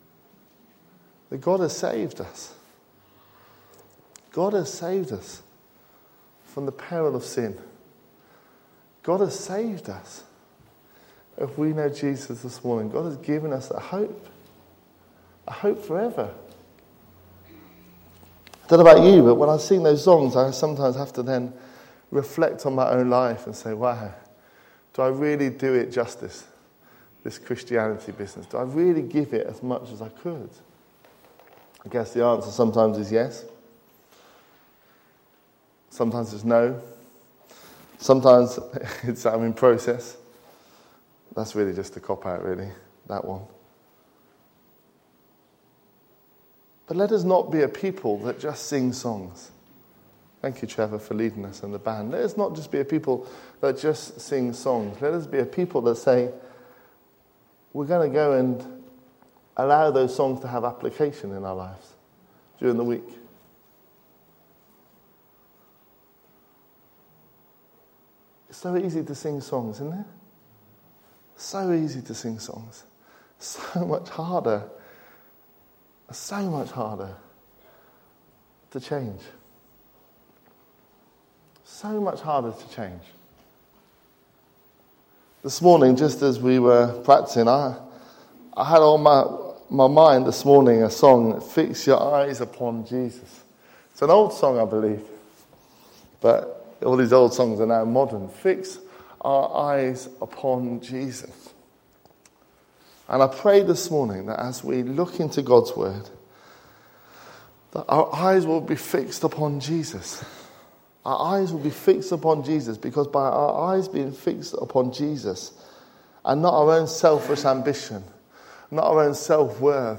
A message from the series "Beatitudes."